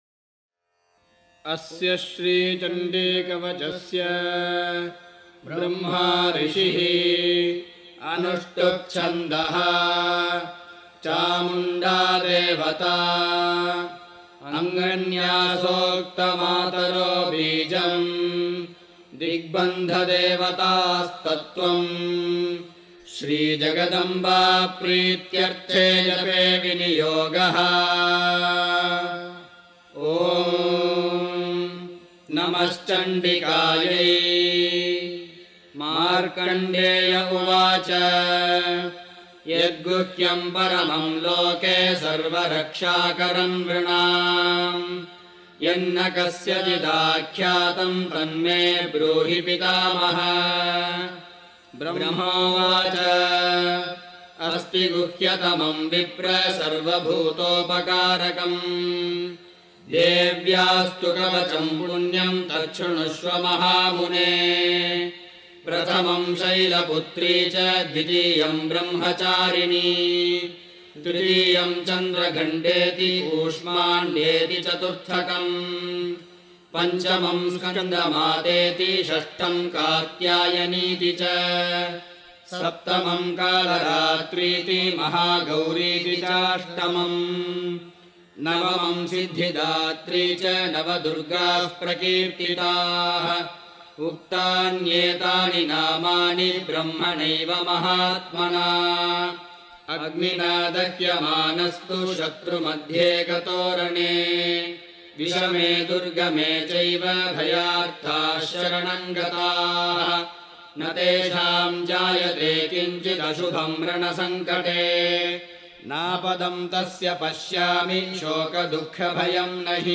chanted